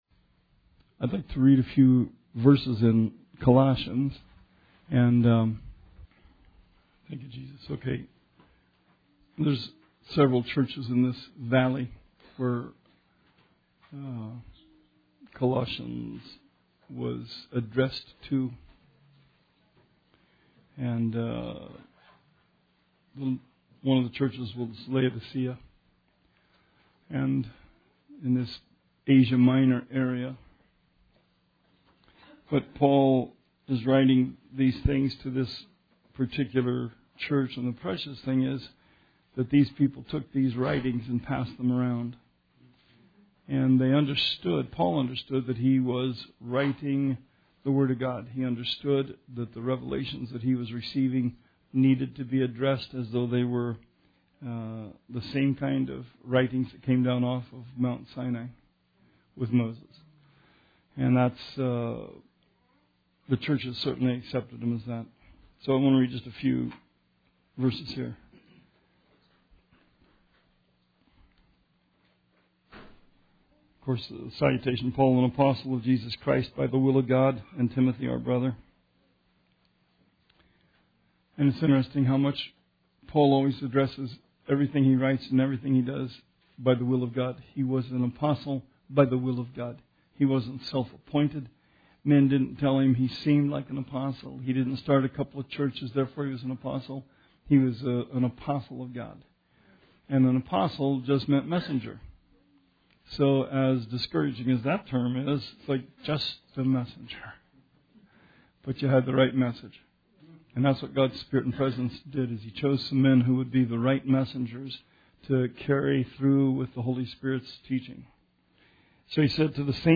Bible Study 4/25/18